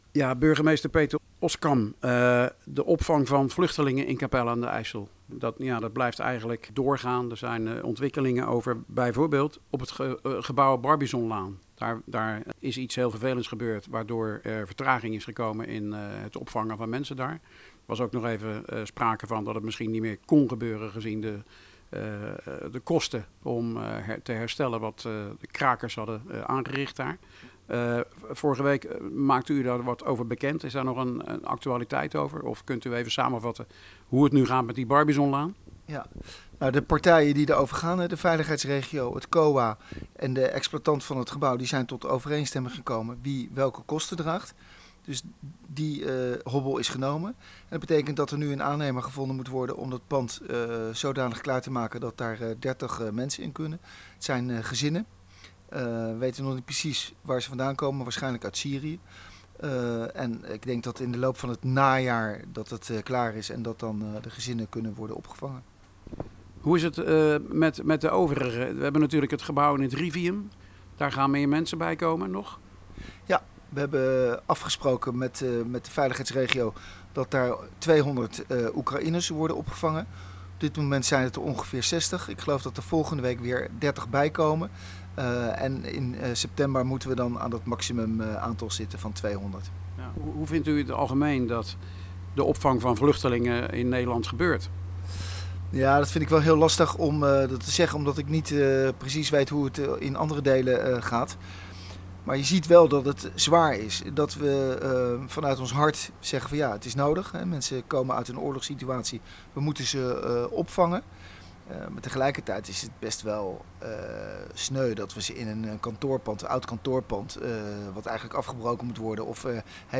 In deze podcast burgemeester Peter Oskam over de opvang van vluchtelingen in Capelle, een gesprek van medio juli.�Het gaat over de locatie Barbizonlaan waar ongeveer 30 mensen zouden worden opgevangen.